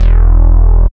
69.01 BASS.wav